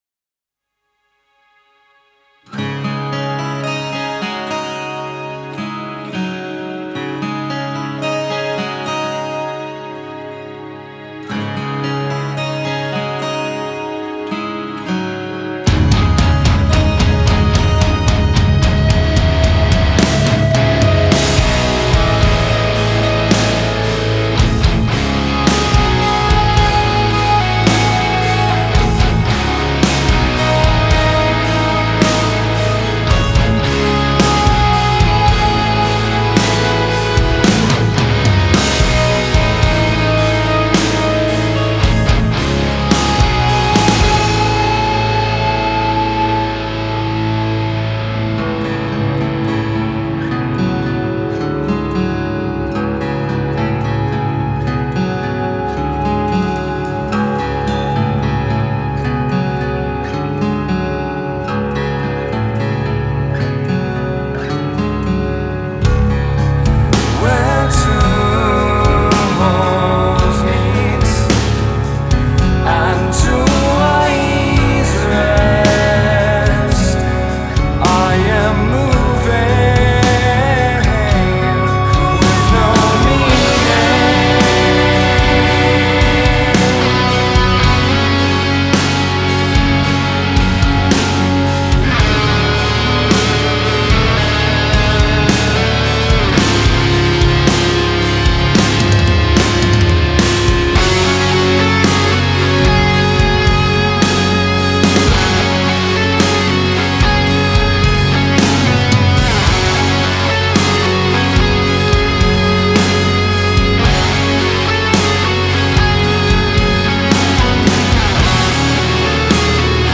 Melodic doom metal